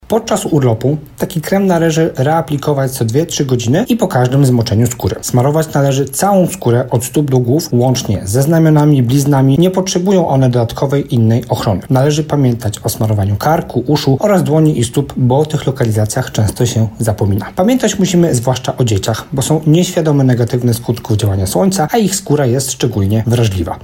dermatolog.